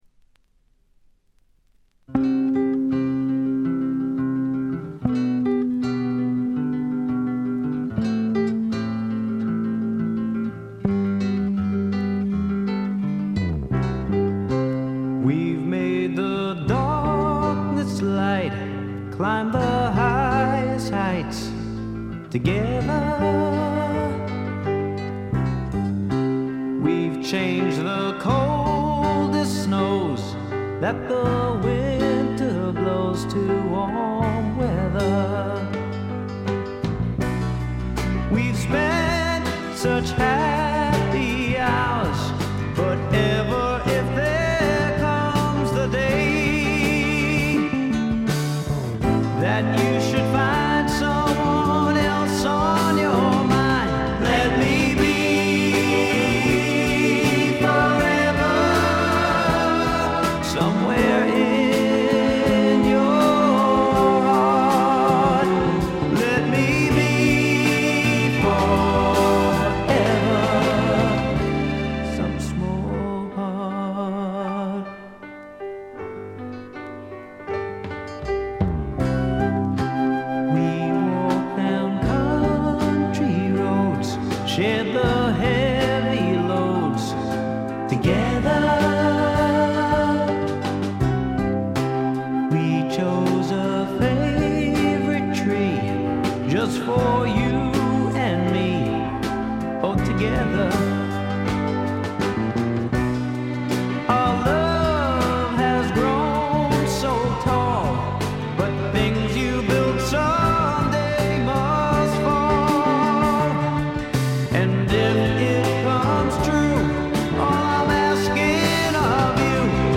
軽微なチリプチ少々。
定番的に聴きつがれてきたプリAOR、ピアノ系シンガーソングライター作品の大名盤です。
試聴曲は現品からの取り込み音源です。
Keyboards, Percussion, Vocals